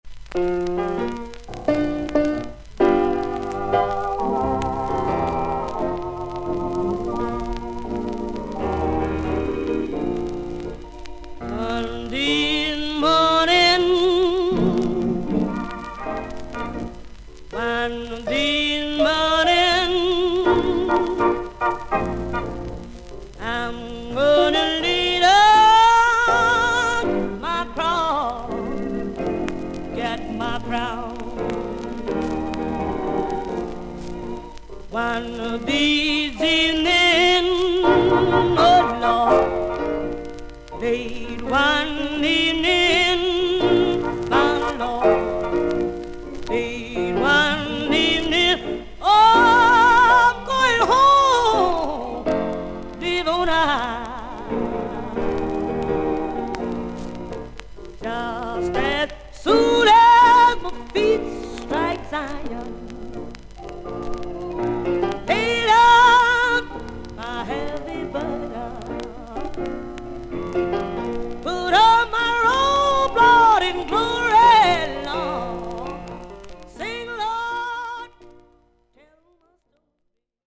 女性ゴスペル・シンガー。
VG+ 少々軽いパチノイズの箇所あり。少々サーフィス・ノイズあり。